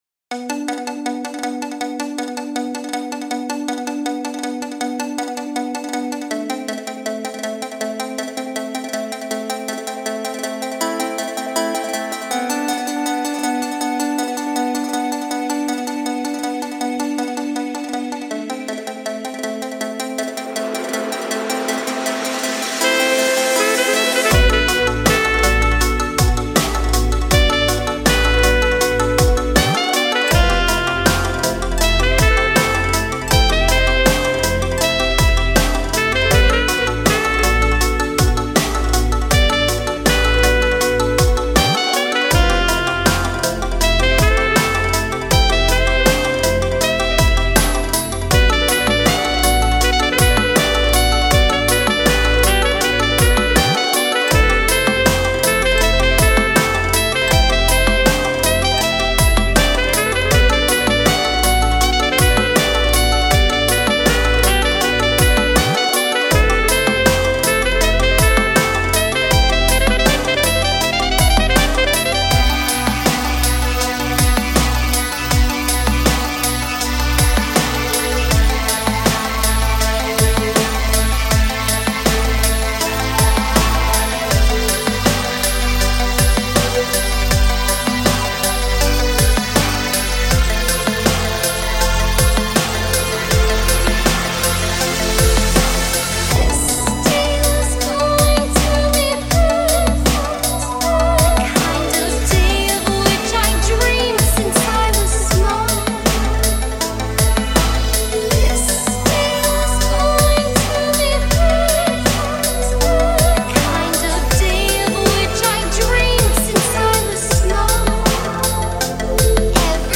Made in FL Studio. Synths include Massive.